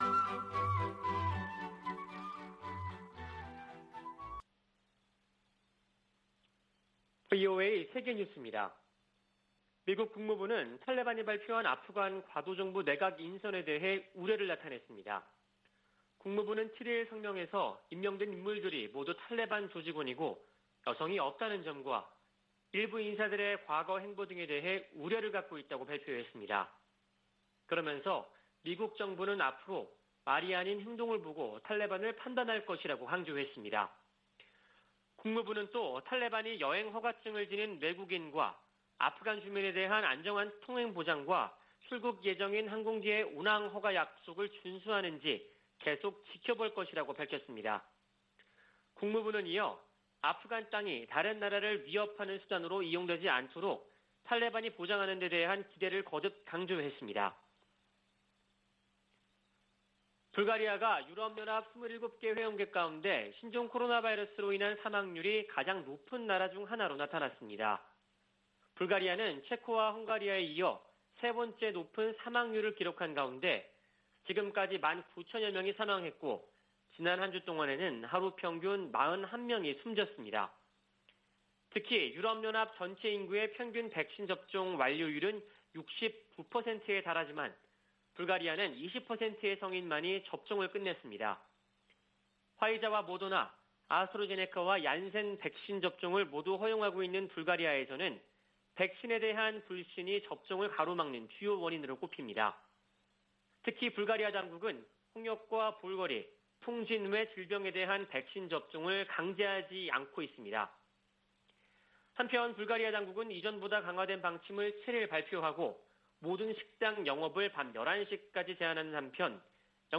VOA 한국어 아침 뉴스 프로그램 '워싱턴 뉴스 광장' 2021년 9월 9일 방송입니다. 북한이 9일 열병식을 개최한다면 신형 무기 등장 여부가 최우선 관심사 가운데 하나라고 미국의 전문가들은 밝혔습니다. 미국인 10명 중 5명은 북한의 핵 프로그램에 대해 크게 우려하는 것으로 나타났습니다. 중국과 러시아가 유엔 안전보장이사회에서 대북 제재 완화 분위기를 띄우고 있는 가운데 미국은 제재를 계속 이행할 것이라는 입장을 밝혔습니다.